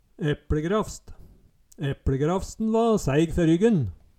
epLegrafst - Numedalsmål (en-US)